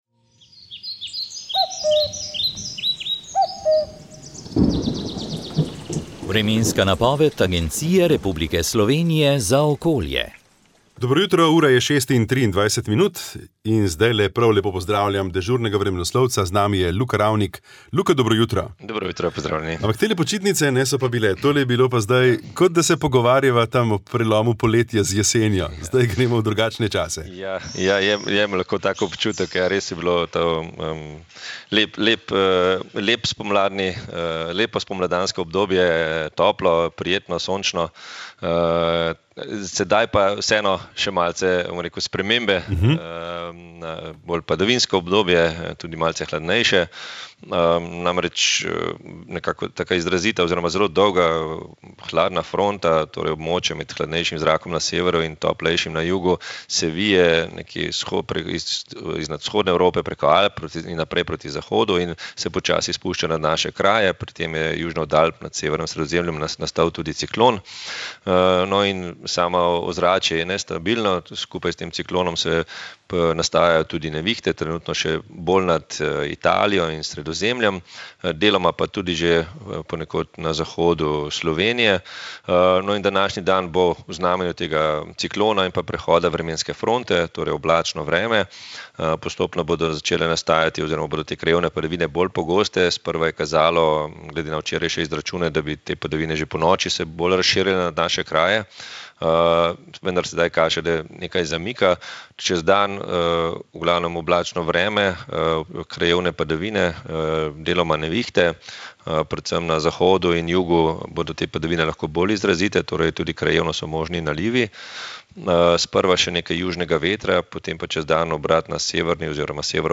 Vremenska napoved 28. april 2025